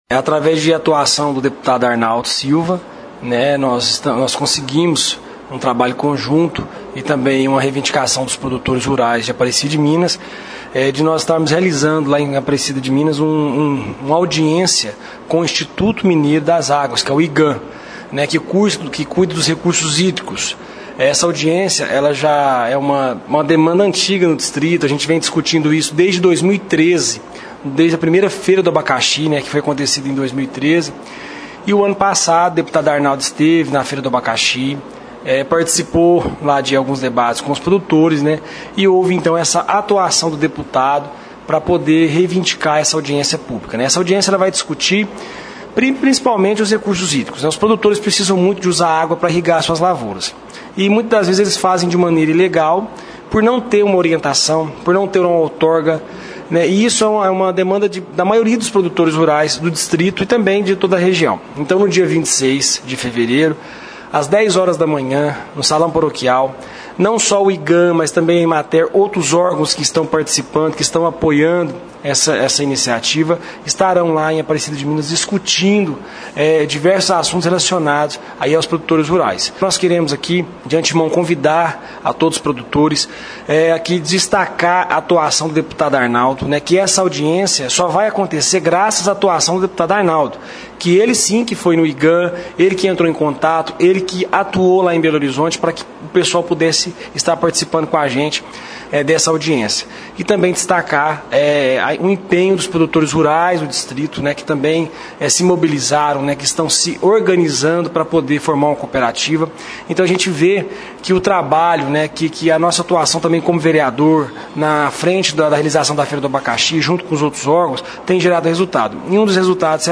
(Clique no player abaixo e ouça a entrevista). O evento acontece amanhã (26/02), e é uma oportunidade para debater sobre o uso correto da água para a irrigação das lavouras na região.